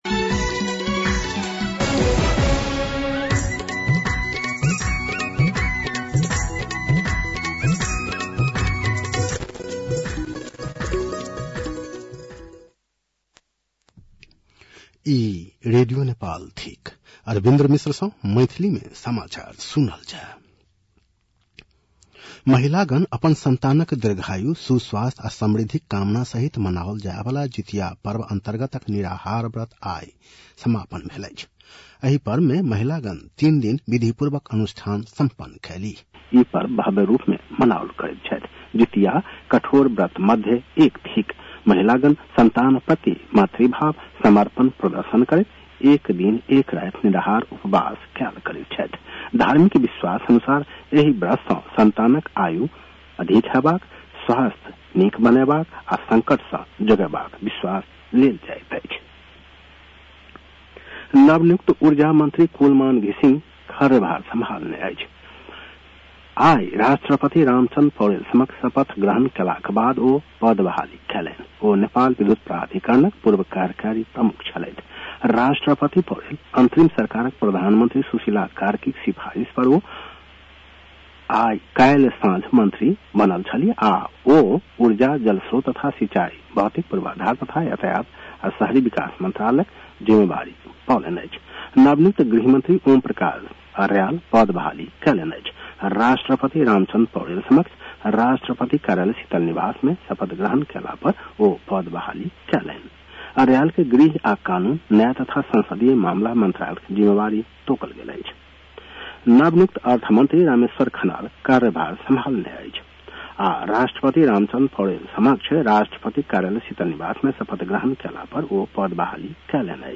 मैथिली भाषामा समाचार : ३० भदौ , २०८२
6.-pm-maithali-news-1-1.mp3